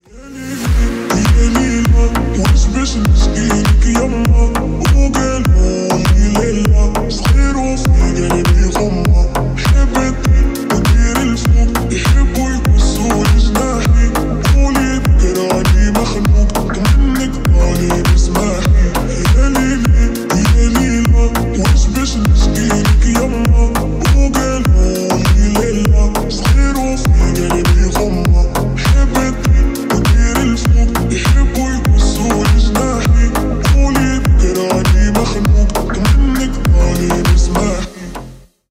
Ремикс
кавказские # клубные